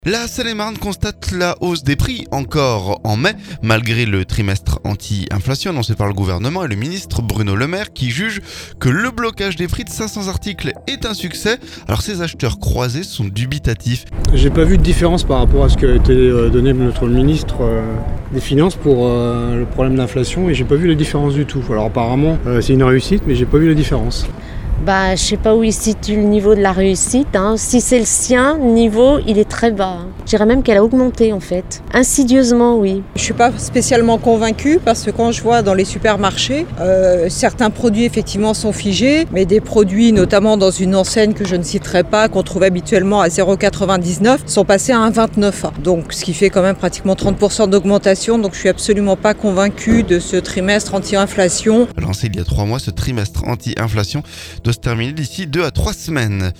Ces acheteurs croisés sont dubitatifs.